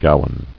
[gow·an]